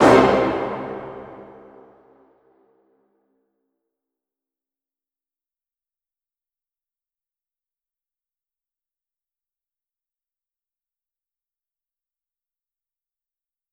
MB Hit (8).wav